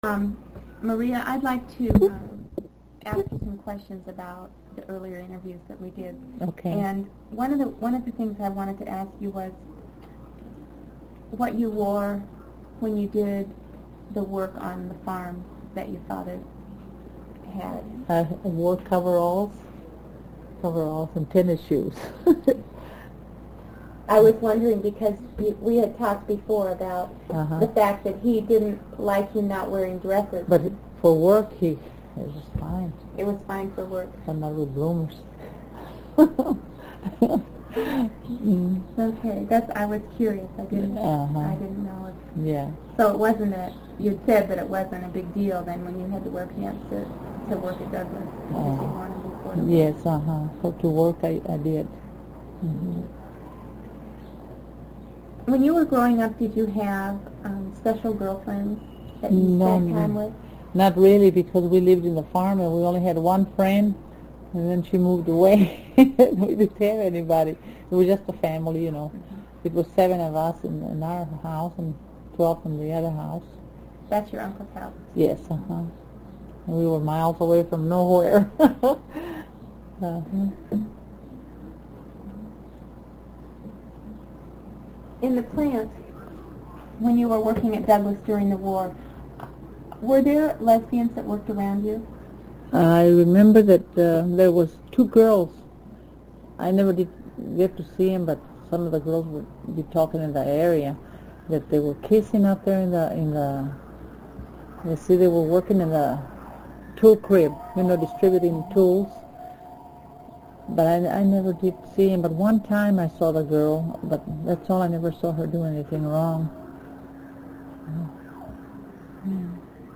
The three interview sessions with her were conducted in the kitchen, around a large booth-type table.